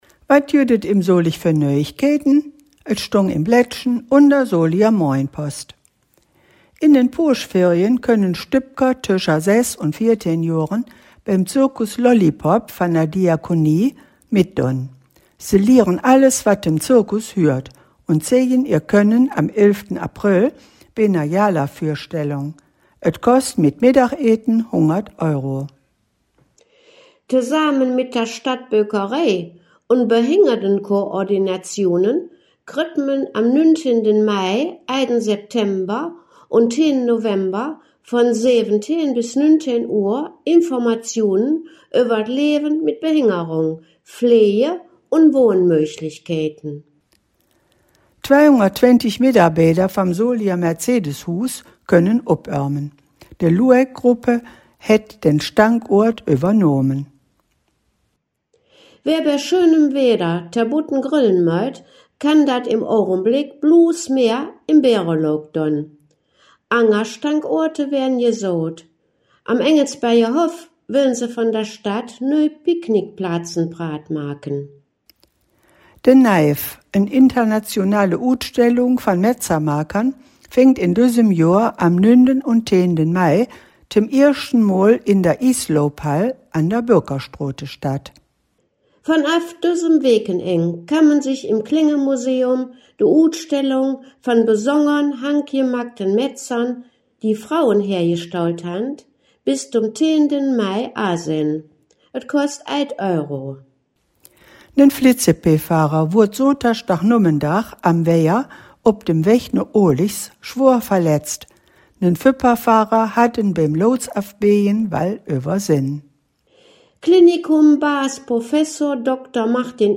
Folge 273 der Nachrichten in Solinger Platt von den Hangkgeschmedden.